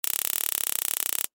Taser Shock 2
Taser Shock 2 is a free sfx sound effect available for download in MP3 format.
yt_ePF2TFZMY4c_taser_shock_2.mp3